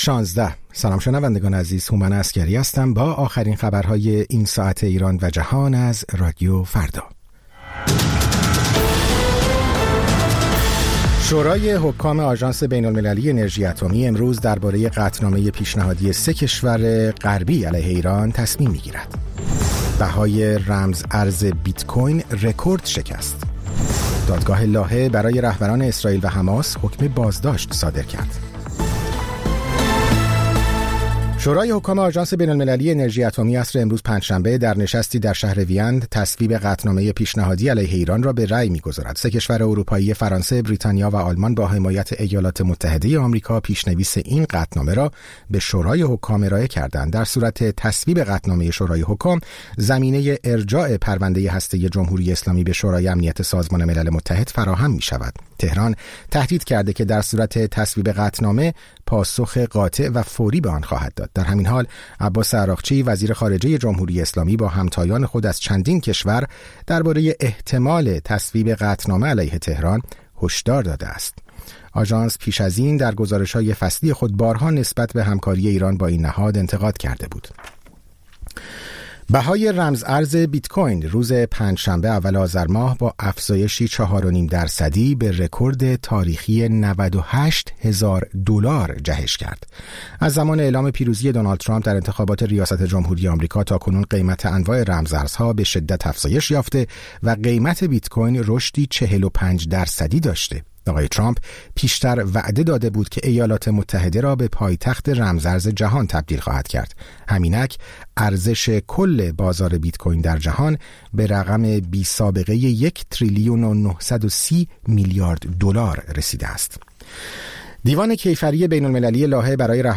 سرخط خبرها ۱۶:۰۰